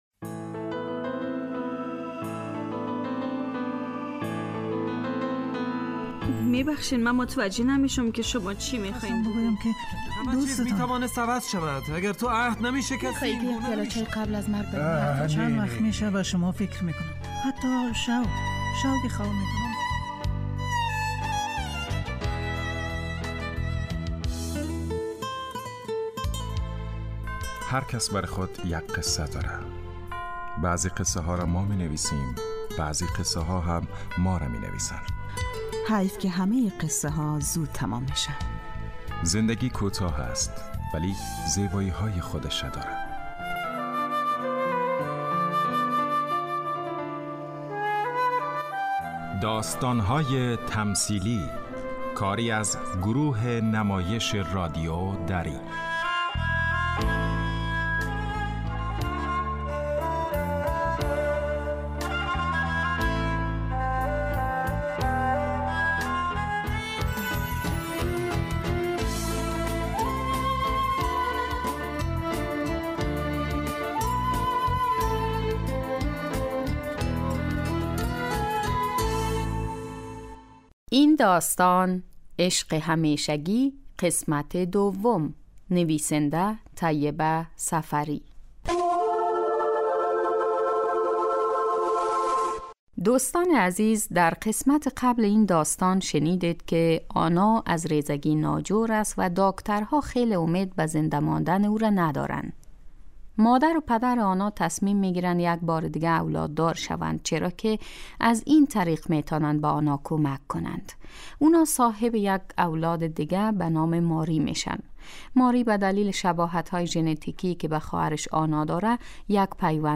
داستان تمثیلی / عشق همیشگی